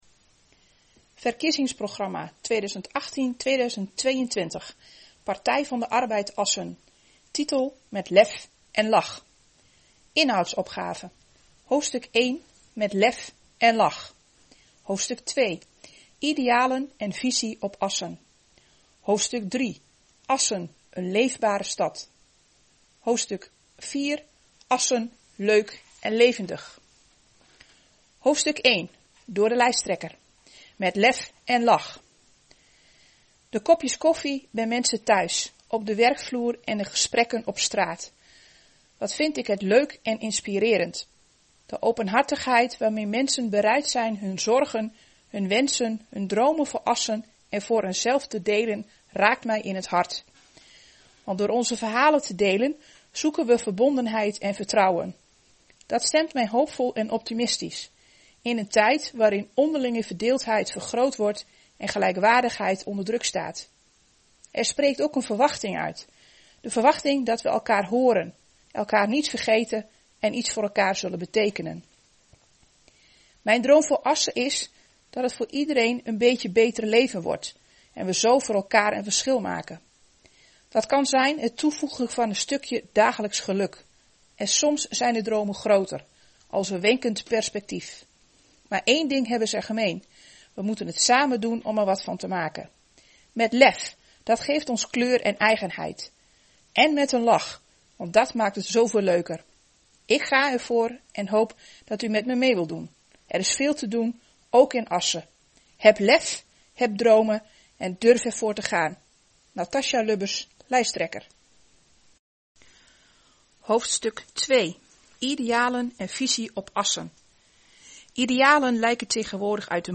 Gesproken verkiezingsprogramma - PvdA Assen